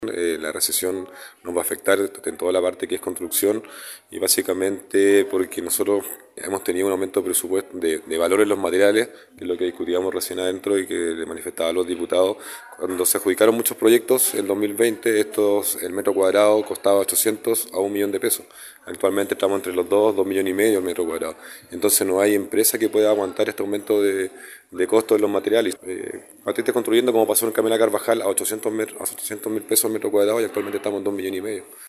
Consejeros regionales y parlamentarios se reúnen en torno a obras paralizadas El Core Rodrigo Arismendi, Secretario Técnico de la Comisión de Obras Paralizadas, explicó que de acuerdo a la realidad que presenta la región, independientemente de las medidas de mitigación que se puedan aplicar, claramente va haber un impacto presupuestario para las obras que ya estaban comprometidas para el próximo año. En ese contexto, el Core por la provincia de Llanquihue agregó que la recesión afectará al sector de la construcción debido al aumento de costo de materiales.